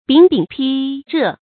炳炳烺烺 bǐng bǐng lǎng lǎng
炳炳烺烺发音